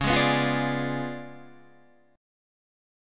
Db7b9 chord